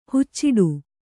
♪ hucciḍu